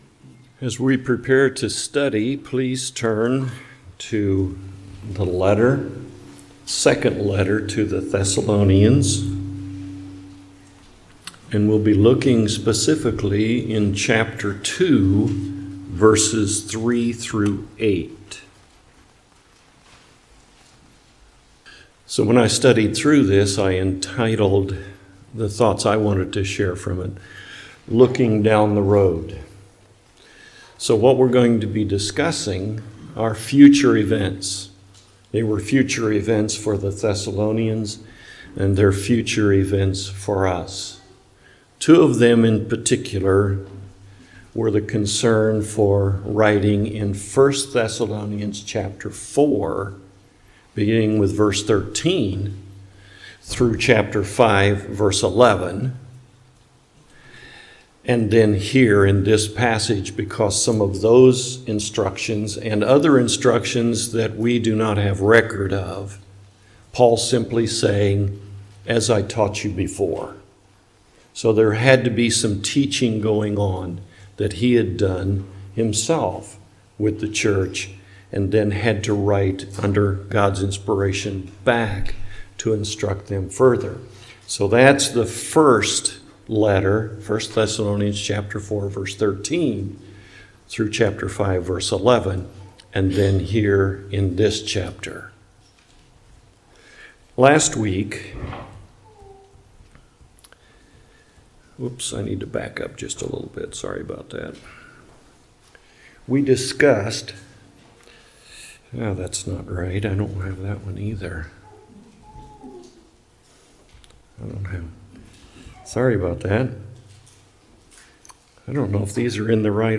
2 Thessalonians Passage: 2 Thessalonians 2:3-8 Service Type: Morning Worship « Looking Down the Road